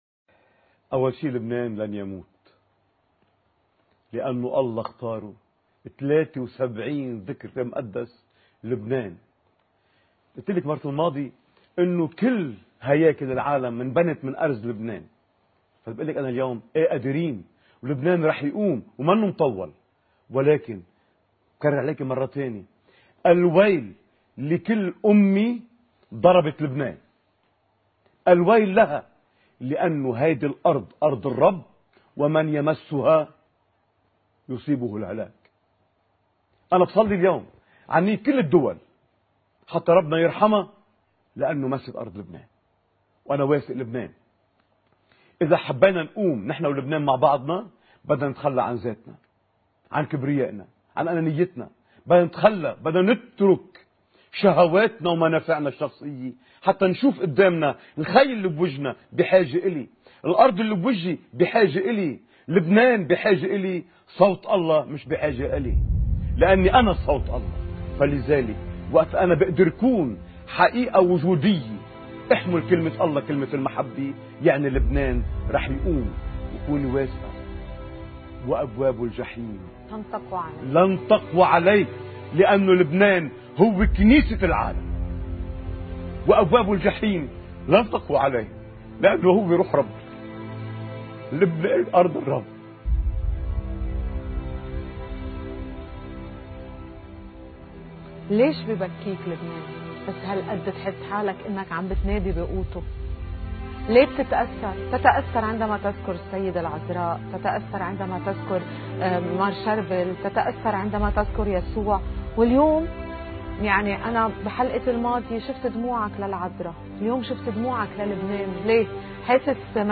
مقتطف من حديث